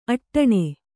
♪ aṭṭaṇe